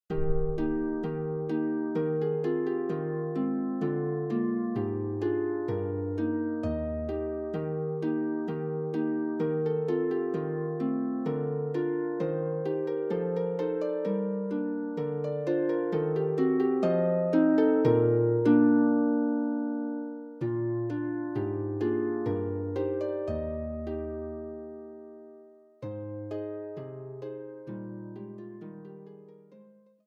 solo pedal harp